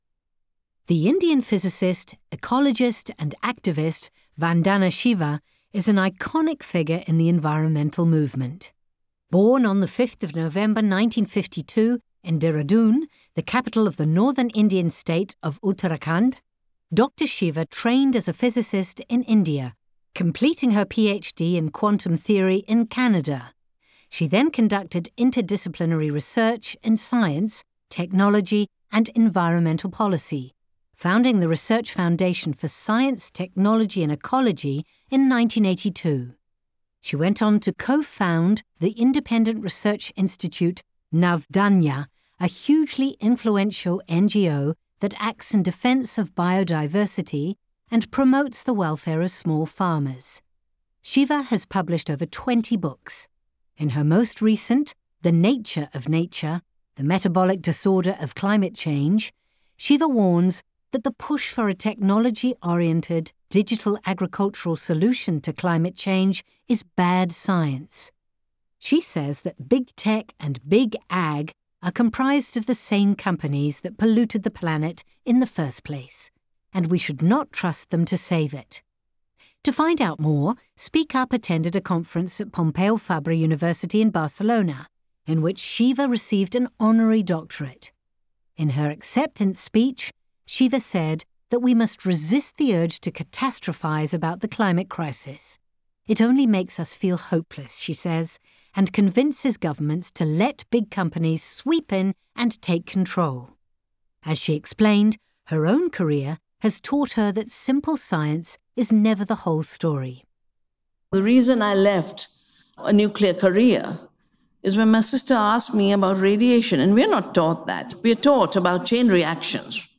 THE BIG PICTURE To find out more, Speak Up attended a conference at Pompeu Fabra University in Barcelona in which Shiva received an honorary doctorate. In her acceptance speech, Shiva said that we must resist the urge to catastrophise about the climate crisis.